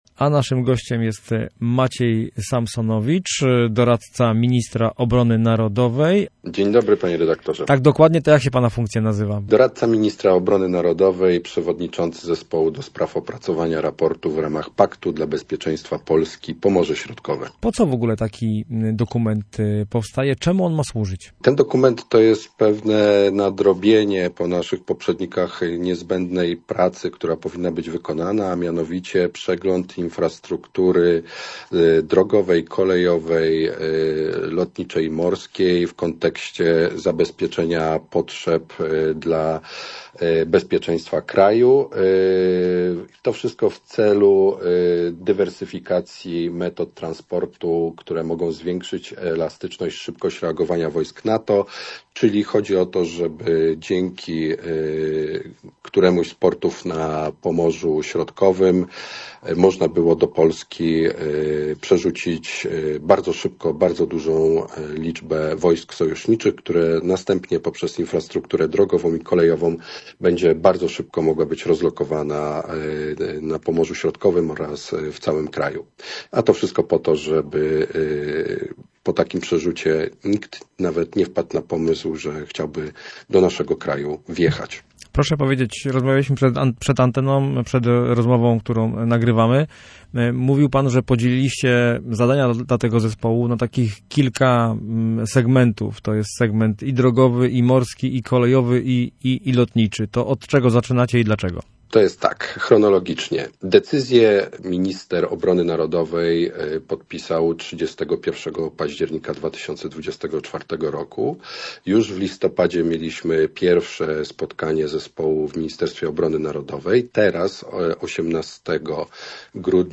Posłuchaj rozmowy z pełnomocnikiem ministra obrony narodowej: https